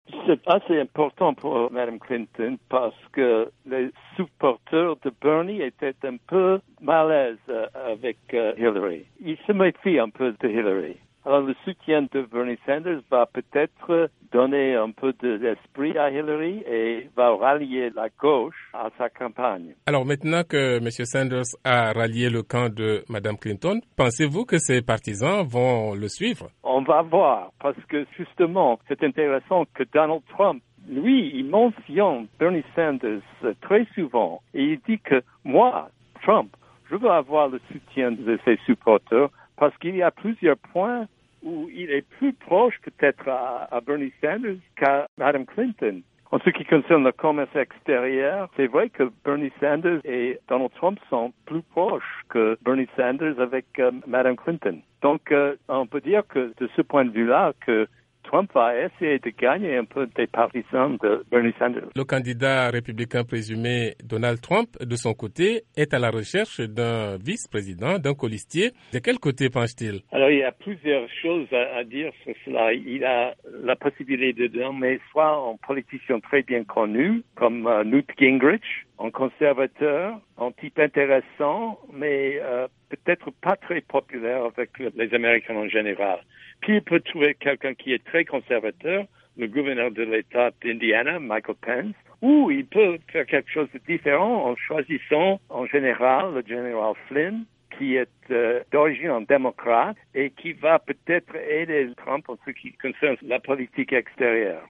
Le professeur